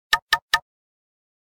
Knock.ogg